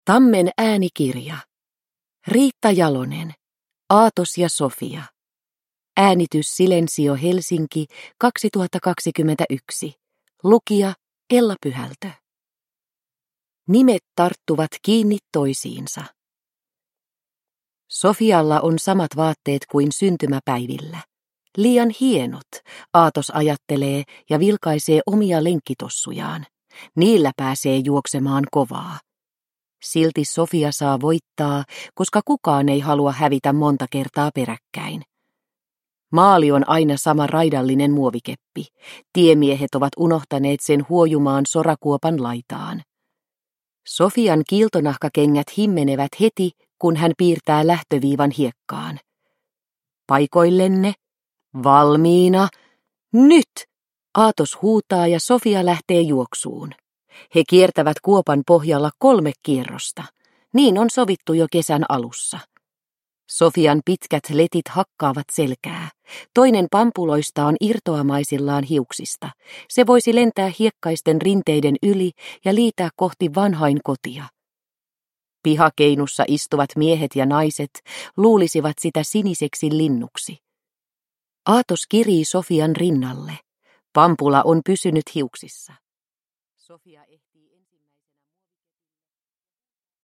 Aatos ja Sofia – Ljudbok – Laddas ner